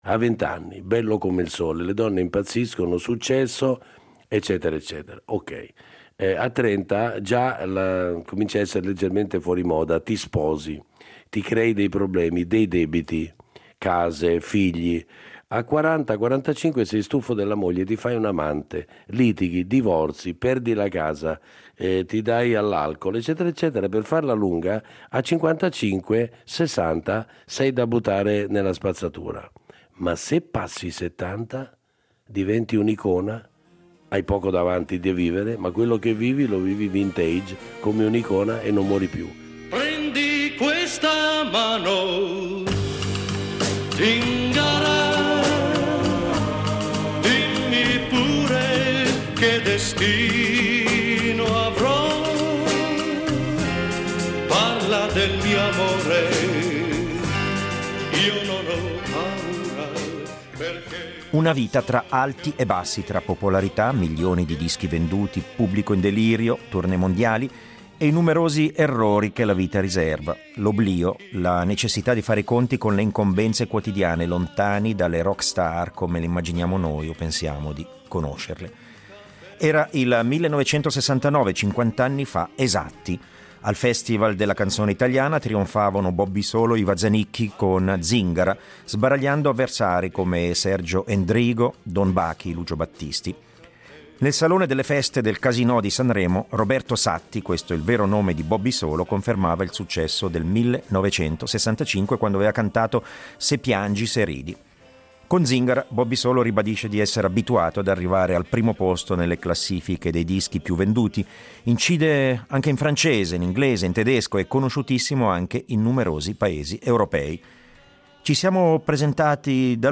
Incontro con il cantautore e artista Bobby Solo , che esattamente cinquant’anni fa trionfava al Festival della Canzone italiana a Sanremo con il brano "Zingara" .
Uno spaccato irripetibile di una realtà indimenticabile, raccontata a "Laser" da Bobby Solo anche in musica con alcuni brani eseguiti accompagnandosi solo con la propria chitarra.